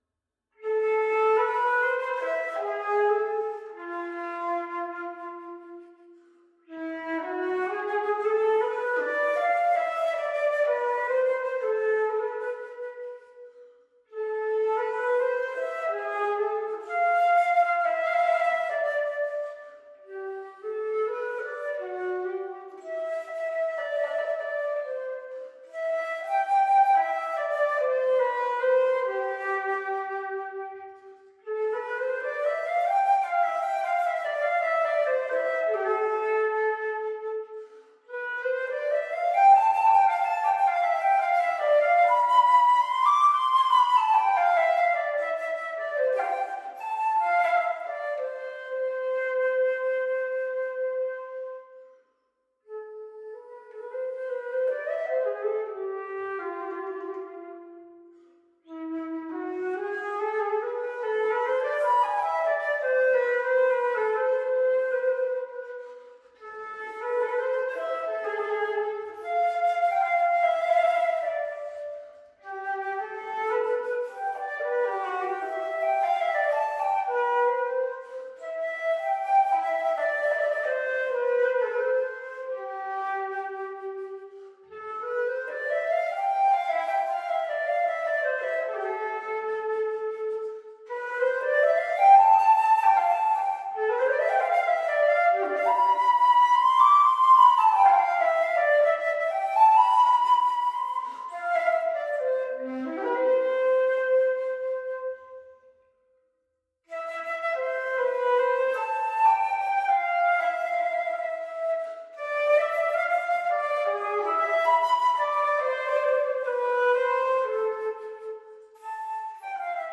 unaccompanied flute
dazzling and virtuosic transcriptions for solo flute